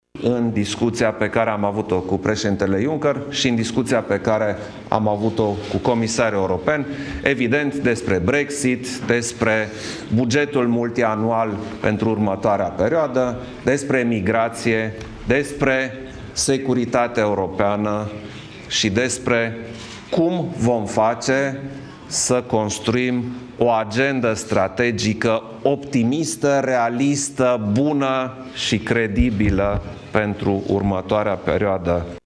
11-ian-declaratie-Iohannis.mp3